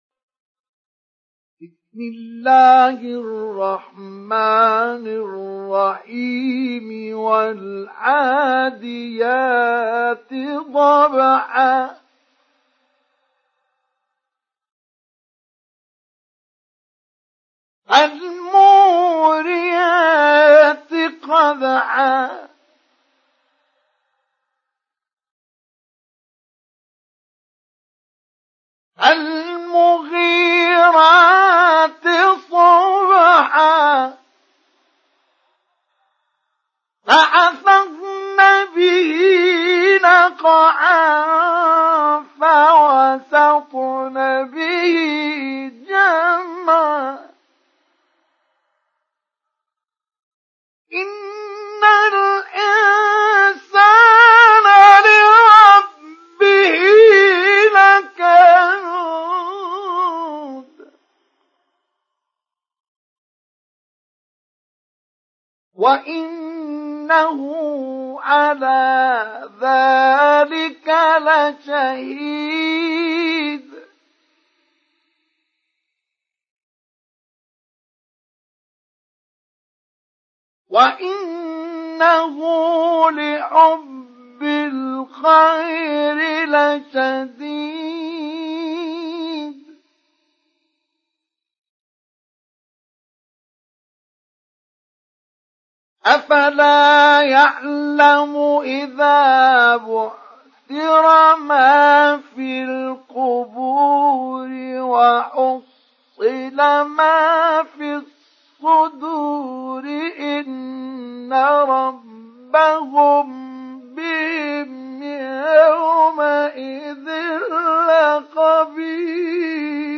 سُورَةُ العَادِيَاتِ بصوت الشيخ مصطفى اسماعيل